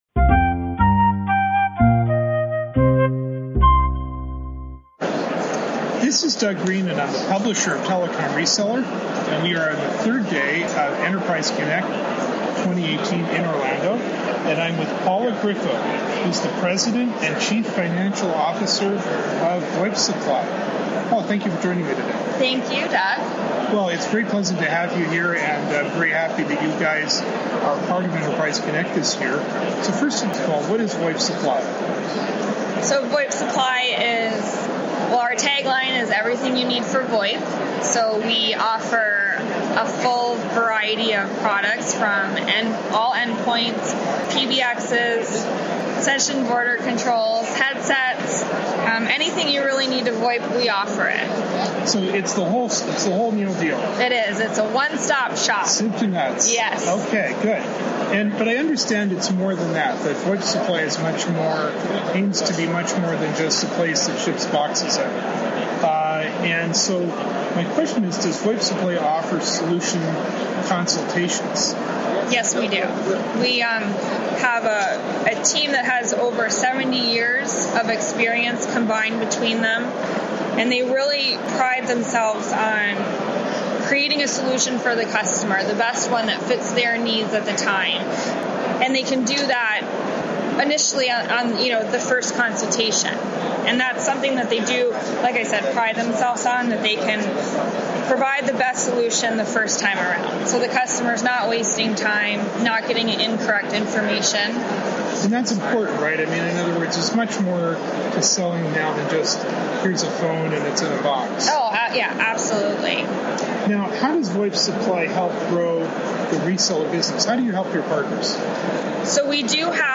This podcast was recorded at Enterprise Connect 2018.